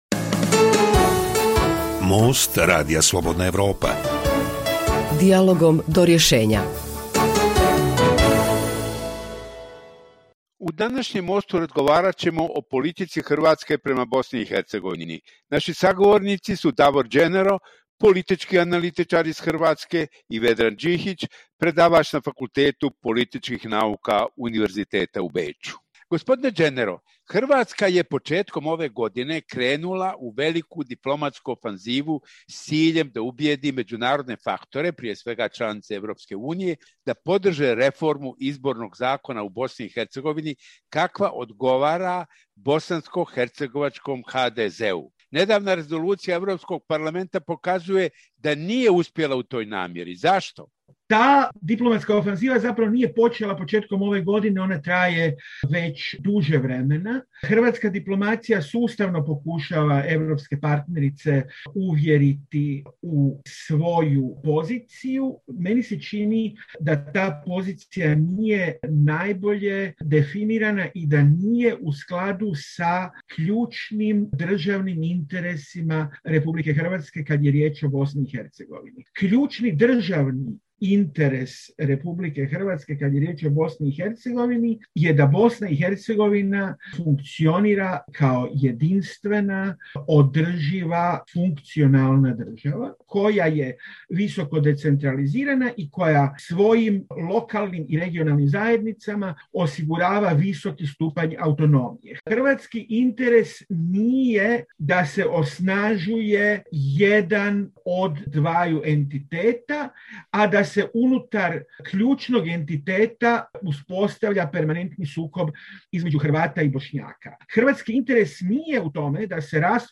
politički analitičar iz Hrvatske